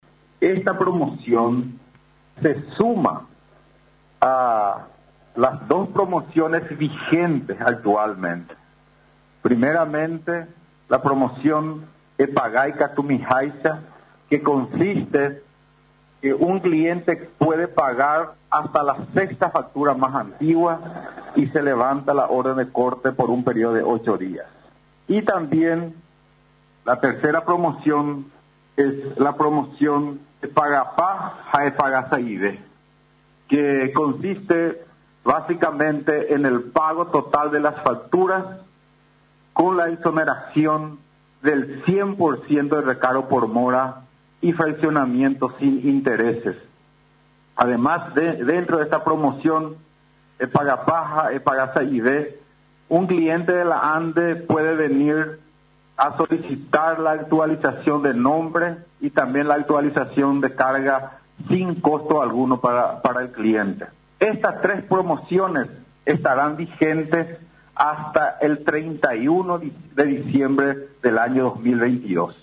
El presidente de la institución, Félix Sosa explicó durante la rueda de prensa, realizada en la sede de la ANDE, que ésta promoción va dirigida a clientes de media tensión (mipymes y grandes clientes) que se encuentran en mora.